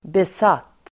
Uttal: [bes'at:]